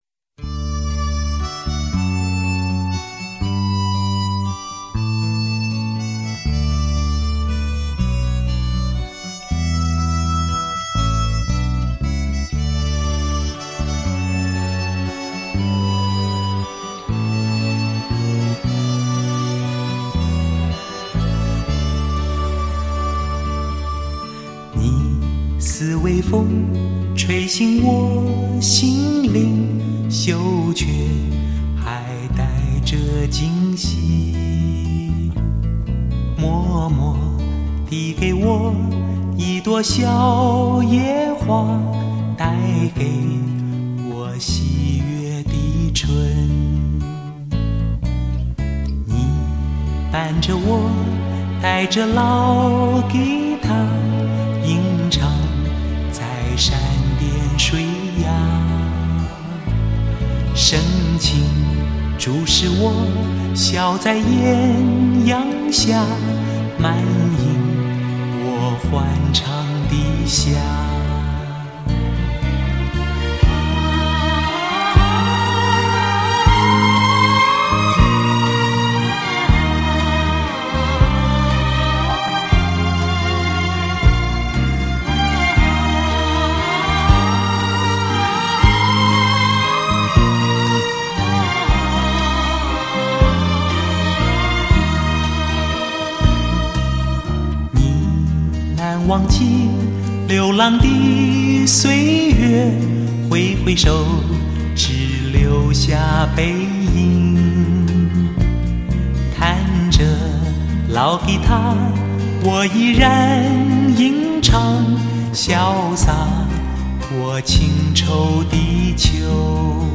男声极品 气势澎湃
情歌白马王子的动人歌声更令人陶醉